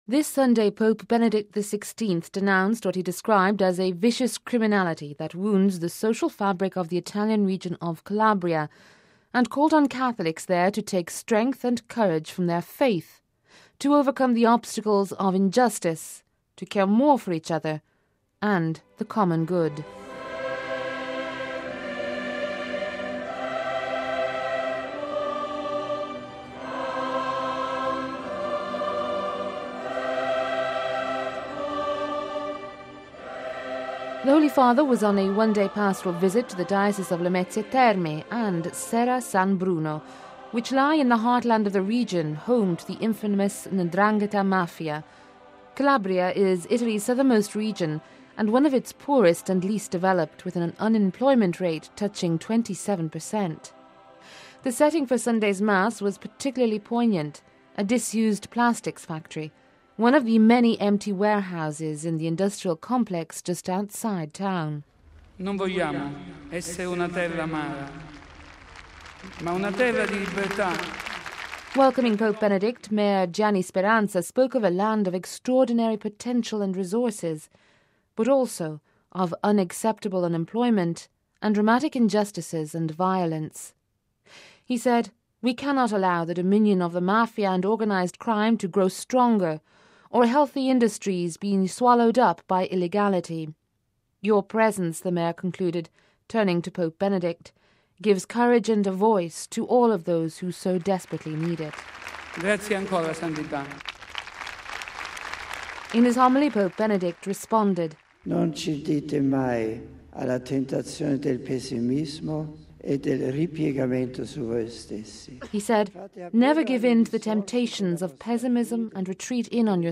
The setting for Sunday’s mass was particularly poignant, a disused plastics factory, one of the many empty warehouses in the industrial complex just outside the town.